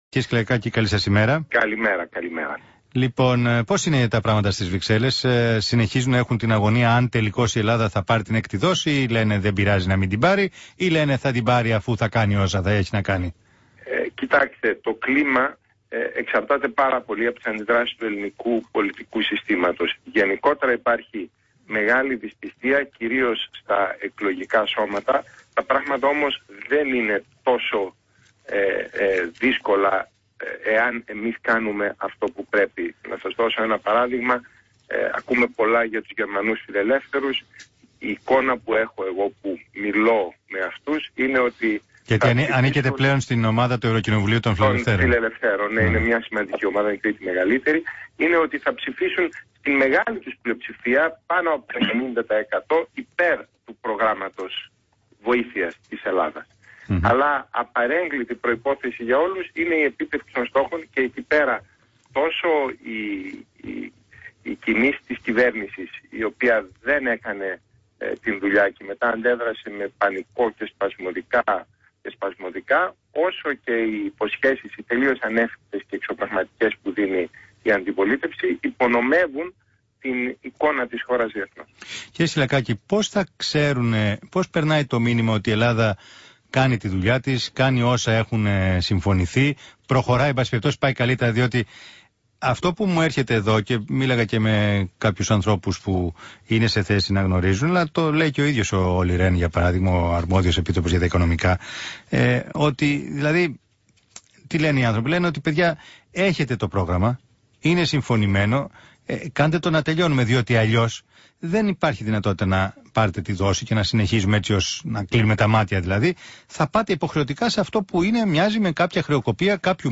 Ακούστε τη συνέντευξη του ευρωβουλευτή Θ. Σκυλακάκη, στο ραδιόφωνο ΣΚΑΪ 100.3 και στον Μπάμπη Παπαδημητρίου.